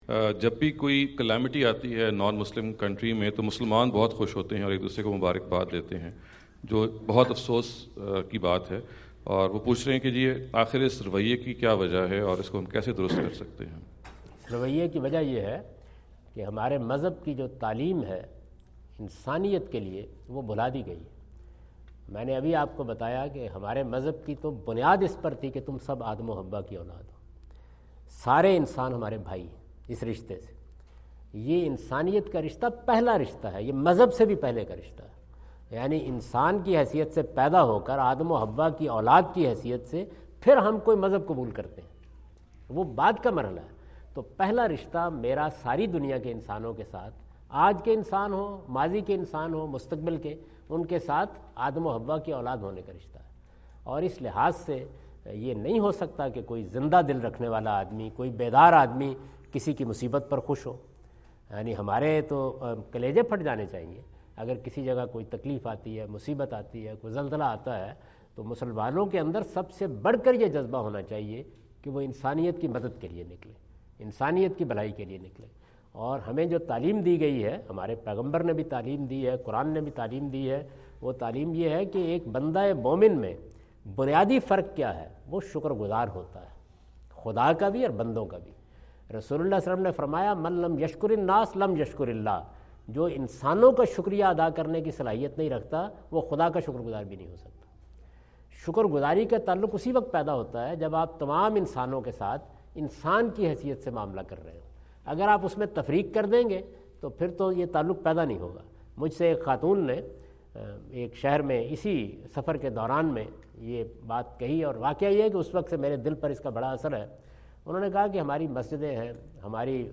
Javed Ahmad Ghamidi answer the question about "Attitude of Muslims towards Miseries of Non-Muslims" asked at Aapna Event Hall, Orlando, Florida on October 14, 2017.